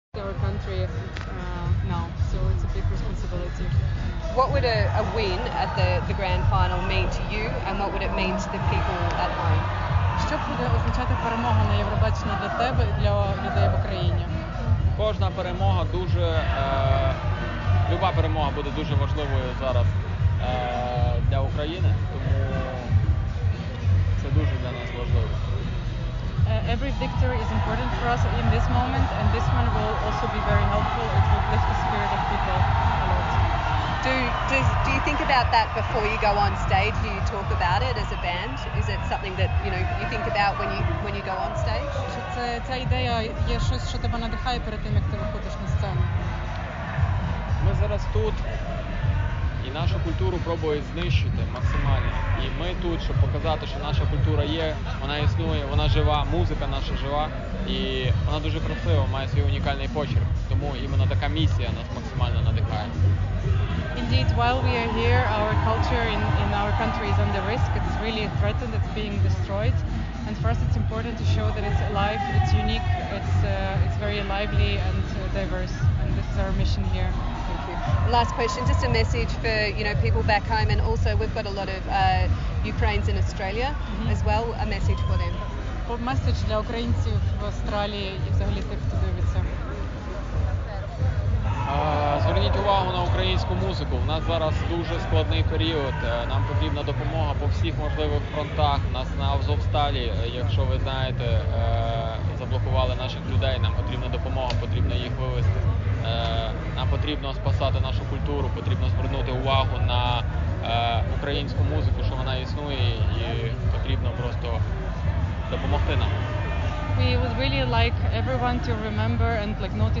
Розмова SBS у Туріні після першої перемоги українців...